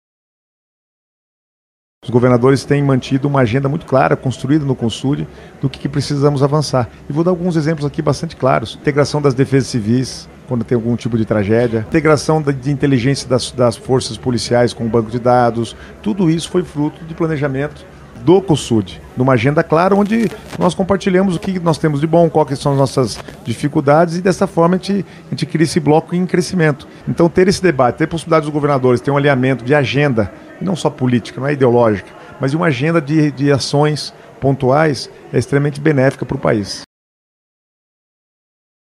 Na cerimônia de abertura, realizada pela manhã no Teatro Guaíra, o Paraná apresentou iniciativas voltadas à sustentabilidade.